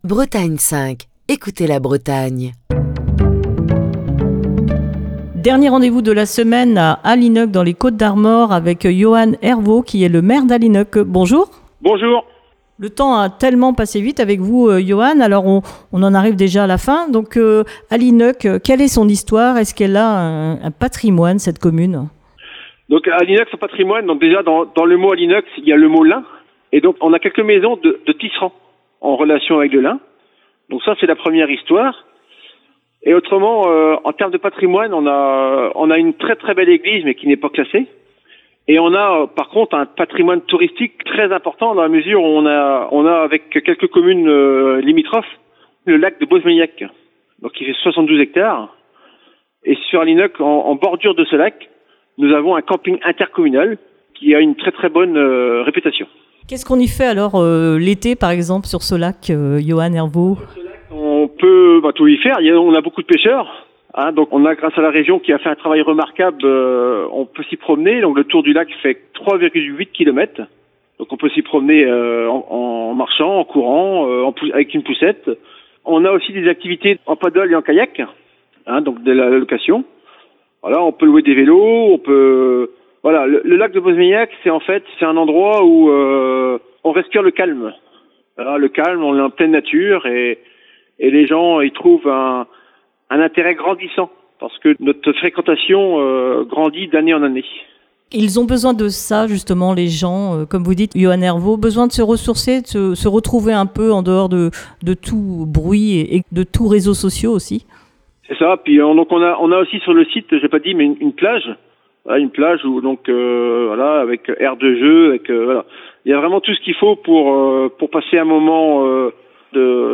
Émission du 6 décembre 2024. Ce vendredi, dernier rendez-vous à Allineuc dans les Côtes-d'Armor.